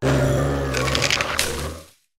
annihilape_ambient.ogg